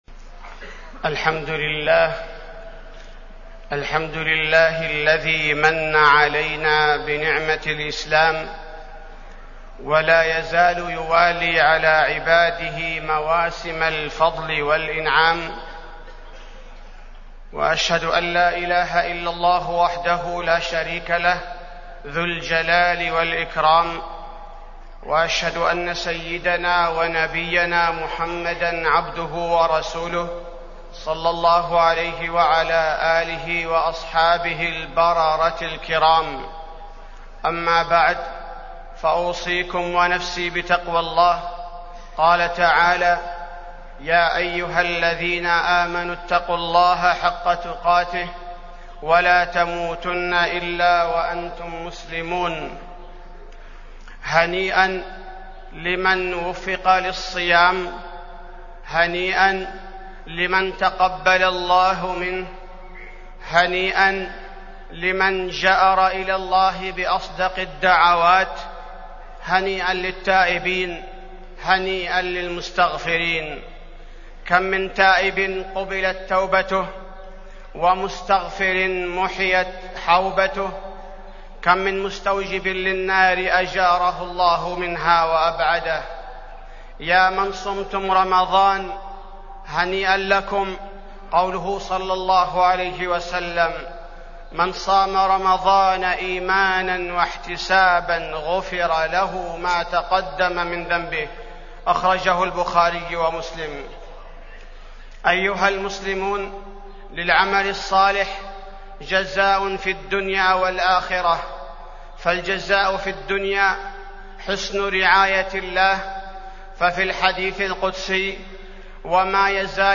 تاريخ النشر ٥ شوال ١٤٢٧ هـ المكان: المسجد النبوي الشيخ: فضيلة الشيخ عبدالباري الثبيتي فضيلة الشيخ عبدالباري الثبيتي الأعمال الصالحة بعد رمضان